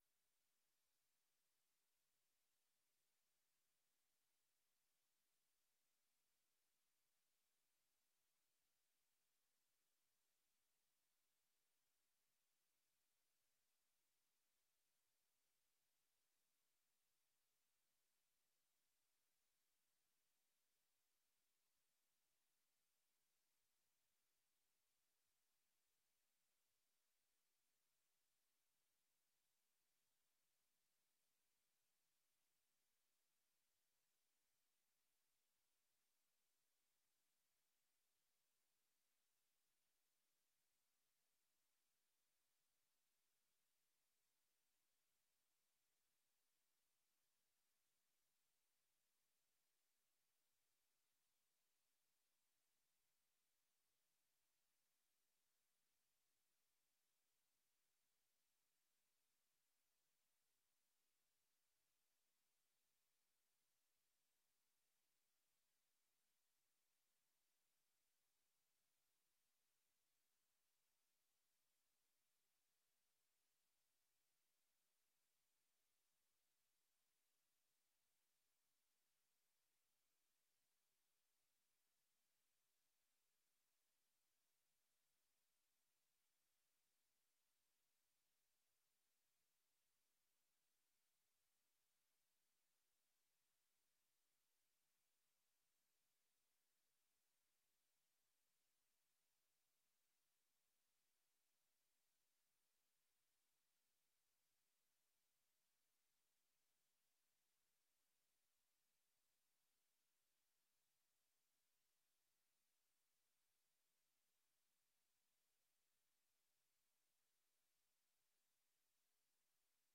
Gemeenteraad 20 januari 2025 19:30:00, Gemeente Den Helder